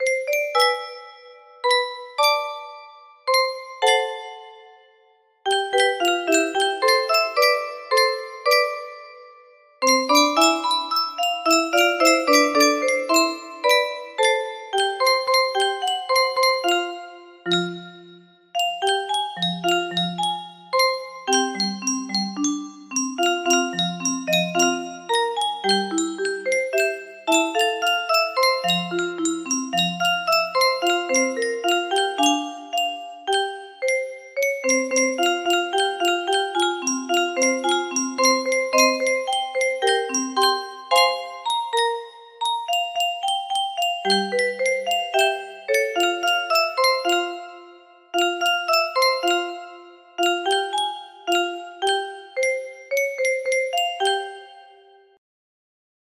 210405 music box melody
Grand Illusions 30 (F scale)